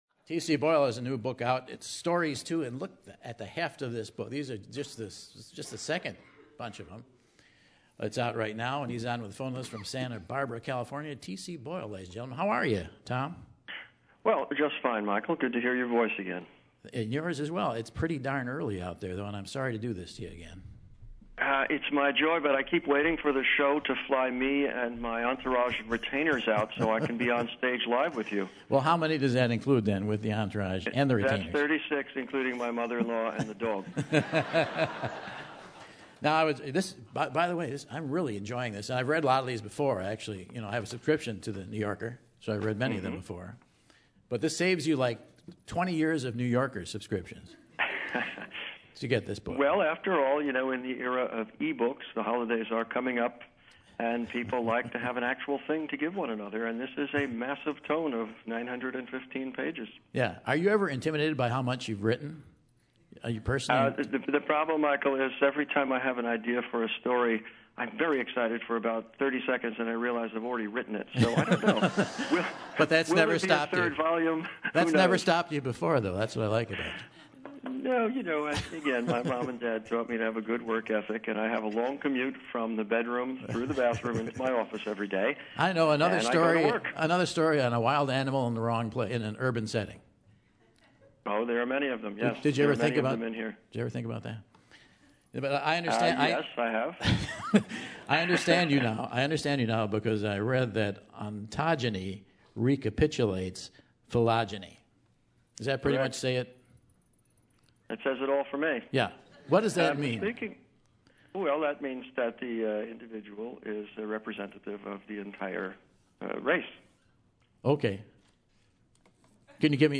Acclaimed author T.C. Boyle chats with Michael about writing, new projects and reads a bit from his latest collection, T.C. Boyle Stories II: The Collected Stories of T. Coraghessan Boyle.